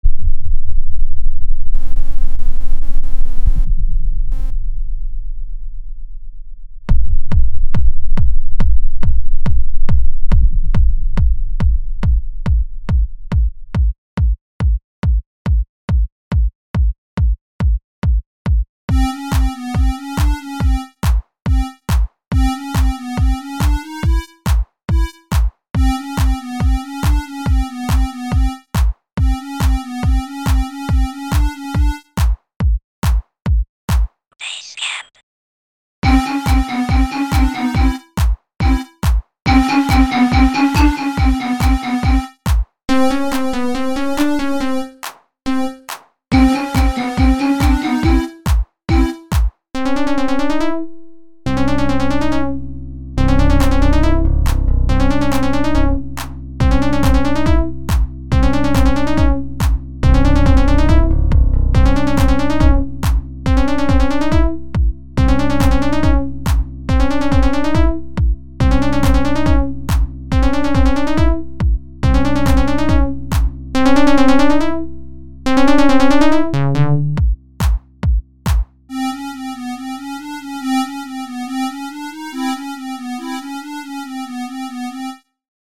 made it in FL studio